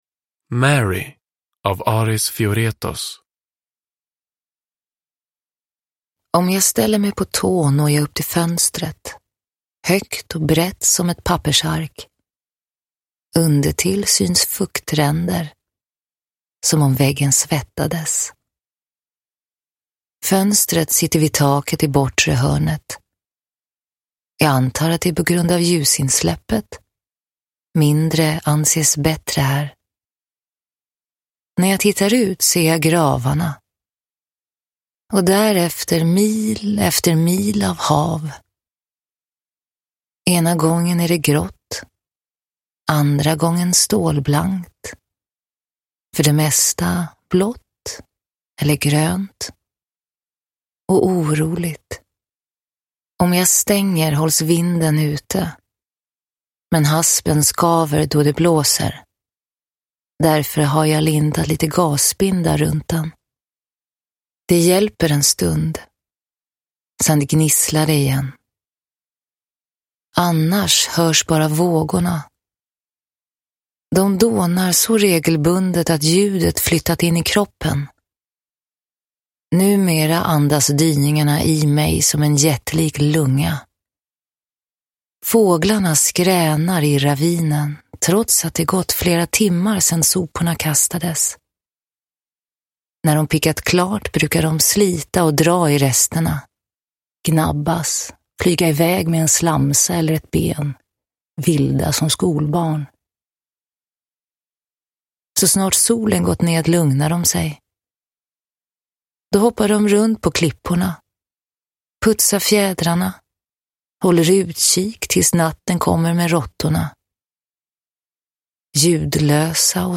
Mary – Ljudbok – Laddas ner